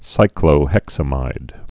(sīklō-hĕksə-mīd, -mĭd)